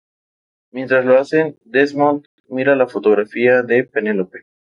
Pronounced as (IPA) /ˈmiɾa/